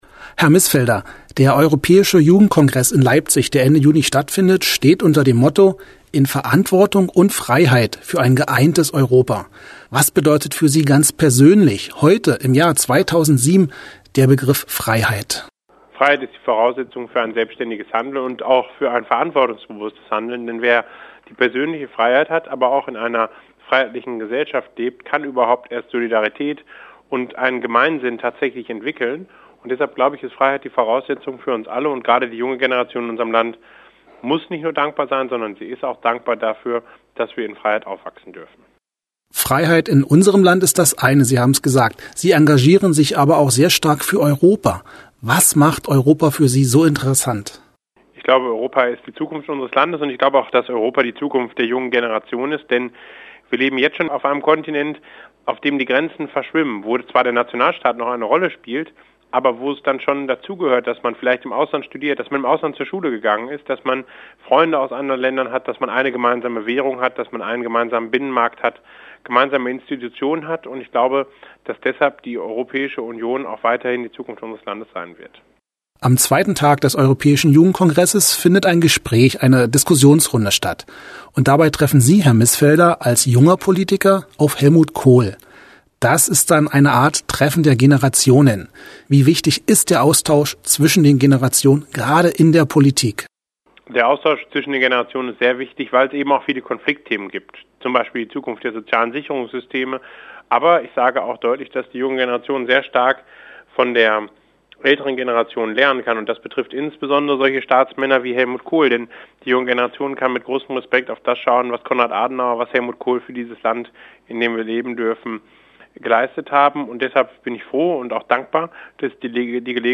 Interview mit dem Bundesvorsitzenden der Jungen Union Deutschlands, Philipp Mißfelder, anlässlich des europäischen Jugendkongresses "In Verantwortung und Freiheit für ein geeintes Europa" am 29./30. Juni 2007 in Leipzig.